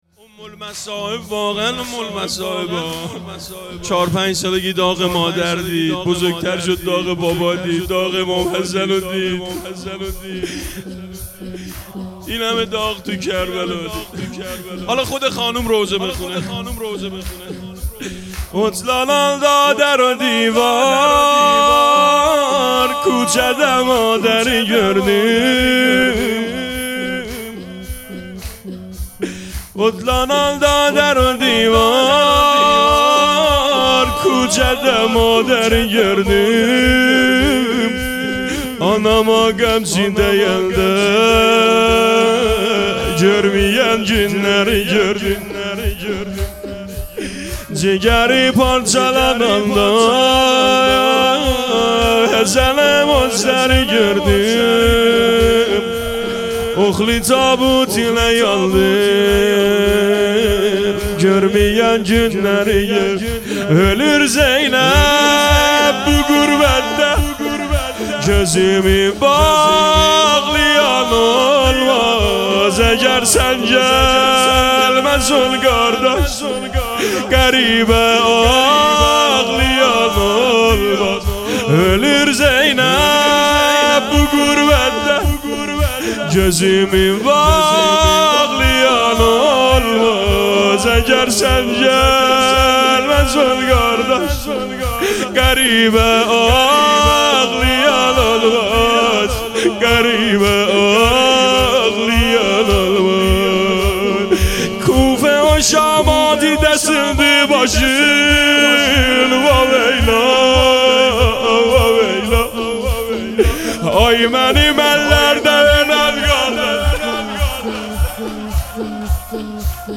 زمینه شب چهارم محرم الحرام 1446
شب چهارم محرم الحرام 1446